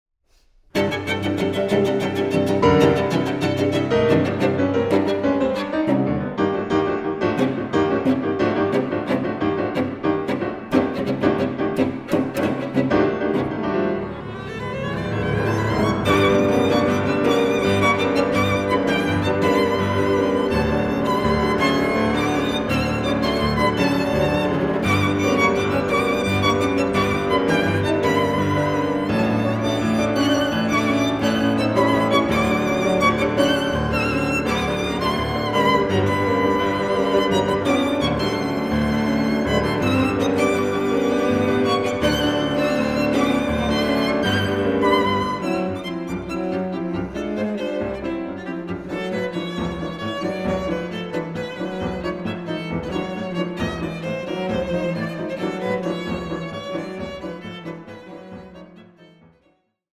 Aufnahme: Festeburgkirche Frankfurt, 2024
II. TSIAJ (“This scherzo is a joke”). Presto